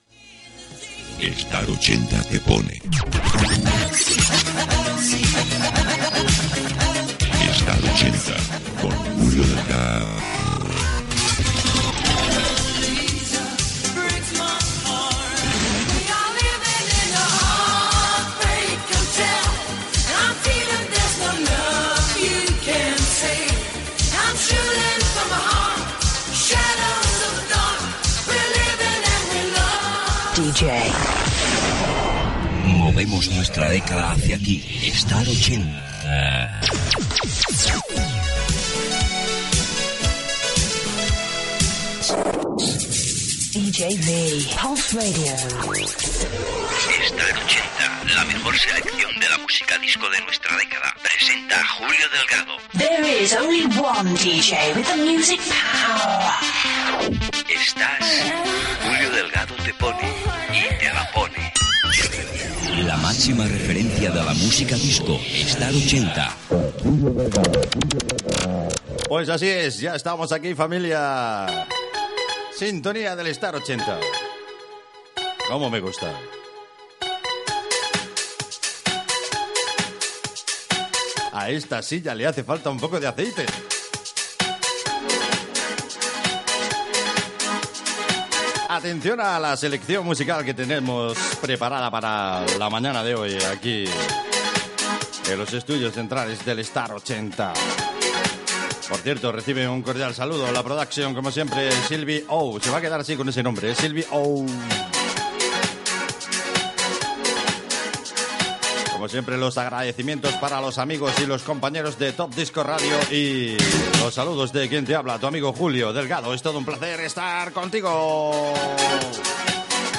Careta, presentació amb identificació de la ràdio i tema musical
Musical
FM